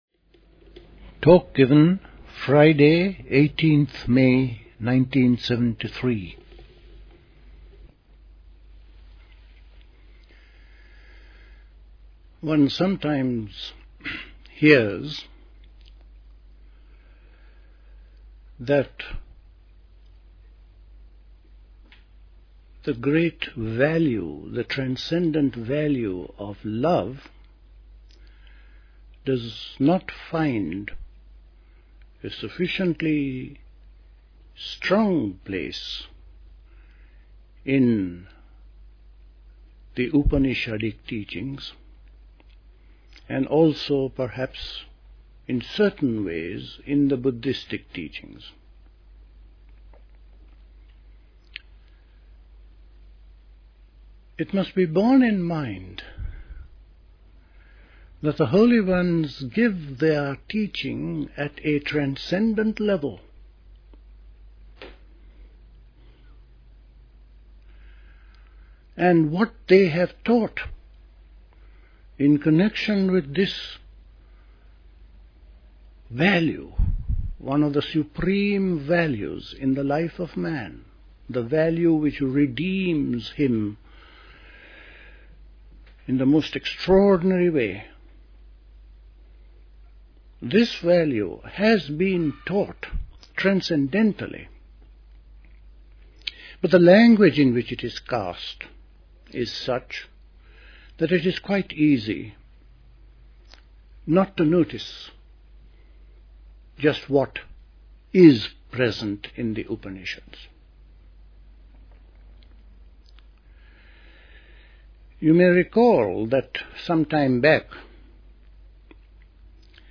A talk
at Dilkusha, Forest Hill, London on 18th May 1973